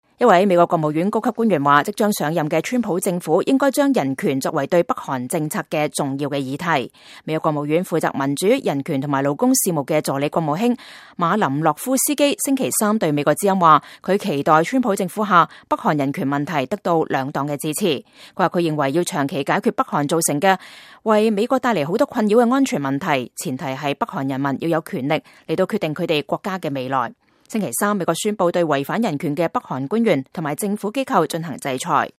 美國國務院助理國務卿馬林諾夫斯基（右）接受美國之音採訪